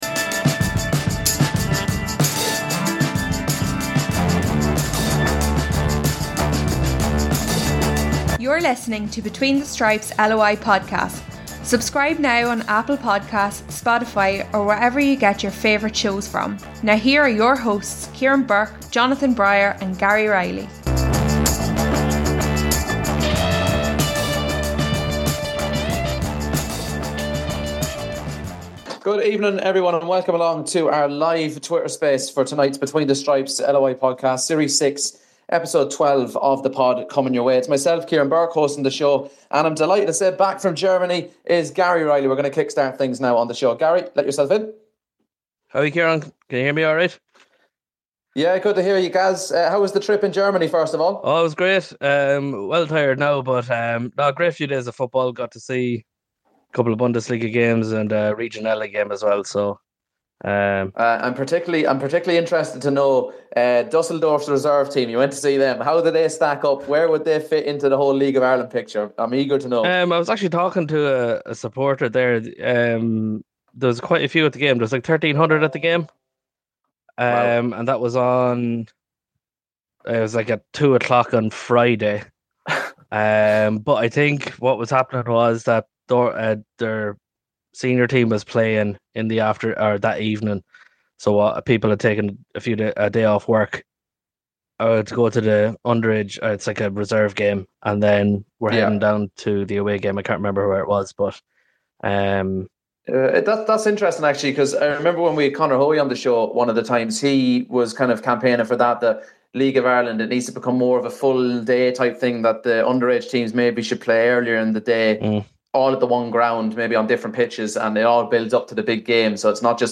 This week's pod is a Twitter live space as we open the floor to our listeners for your calls and comments.